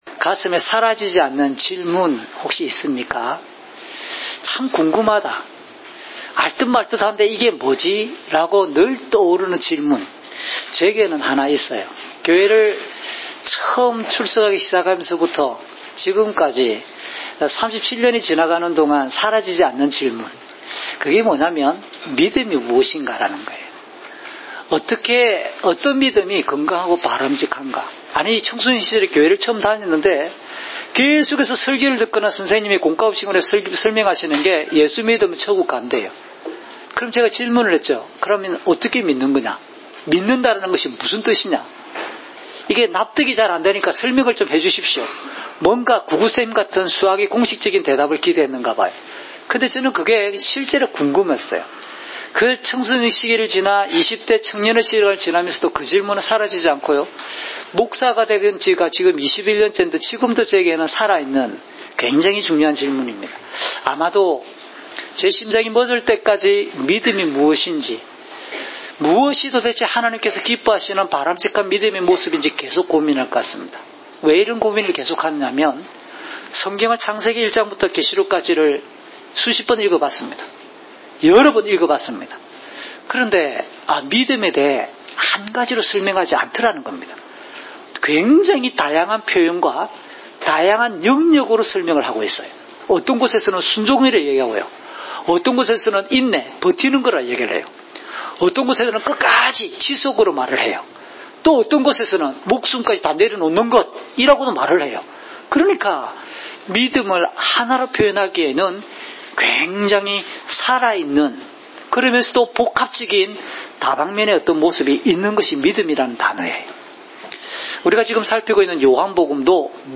주일설교 - 2019년 12월 15일 “우리는 그리스도의 사람들입니다!"(요1:35~51)